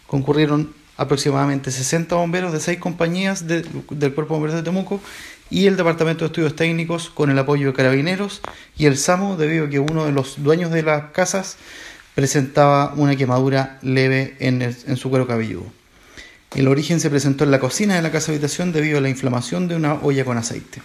cu-incendio-bomberos.mp3